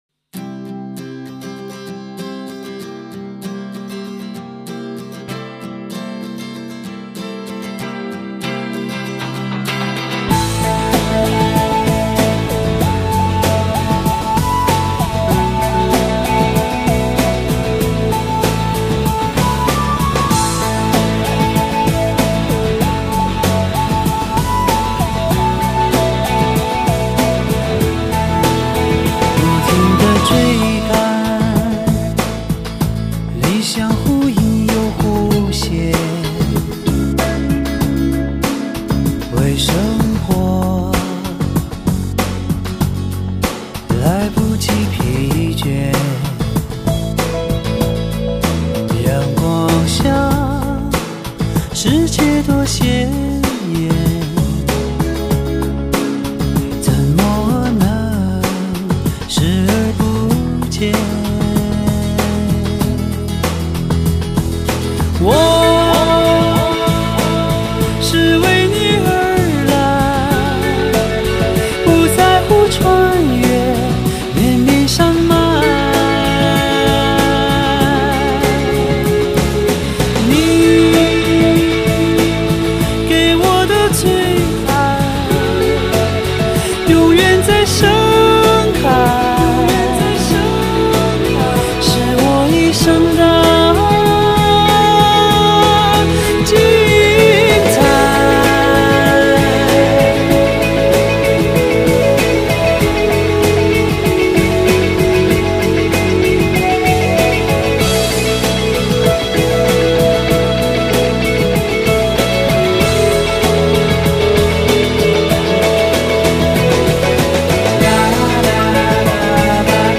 这一首曲风相对大气的作品